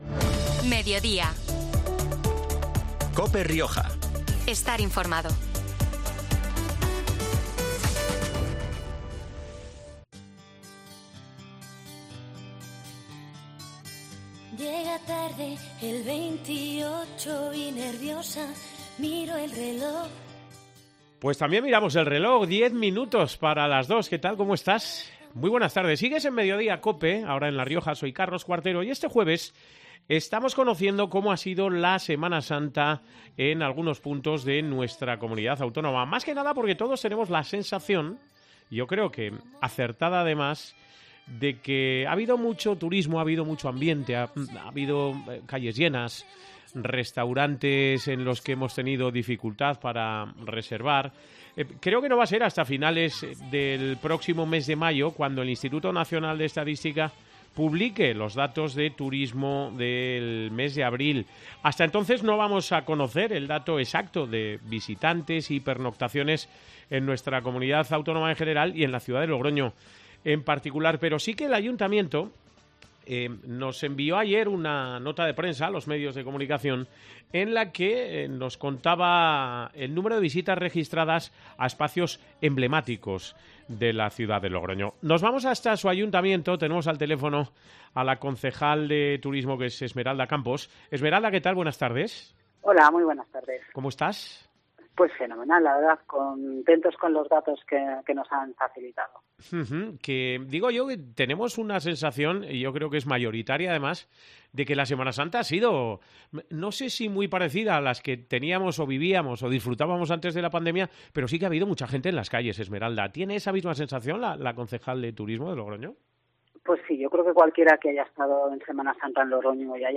Esmeralda Campos, concejal de Turismo, confirmaba esos datos en COPE Rioja.